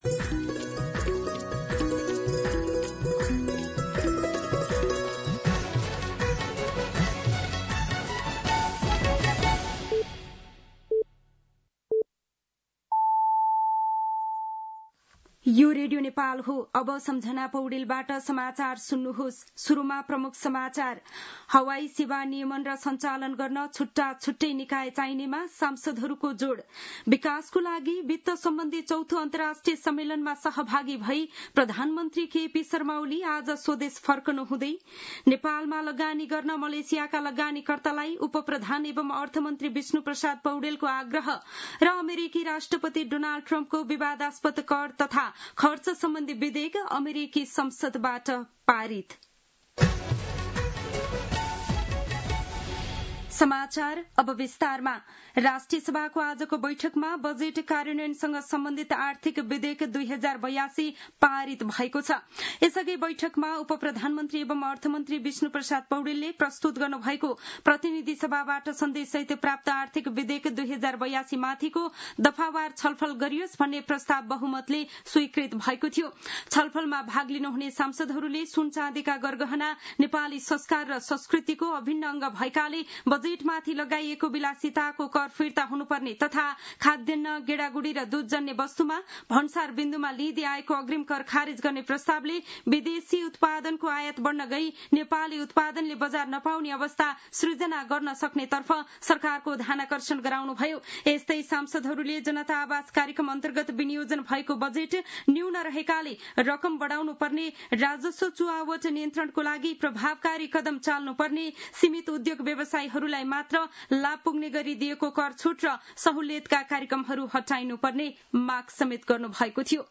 दिउँसो ३ बजेको नेपाली समाचार : १९ असार , २०८२
3-pm-News-3-20.mp3